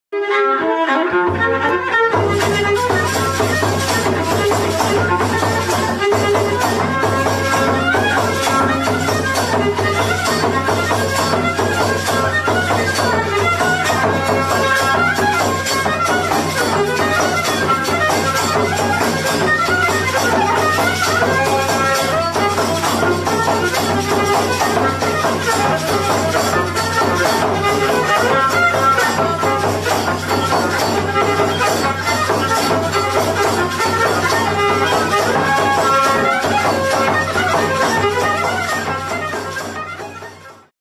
Oberek
organki
nagr. Konstantów, 2004
bębenek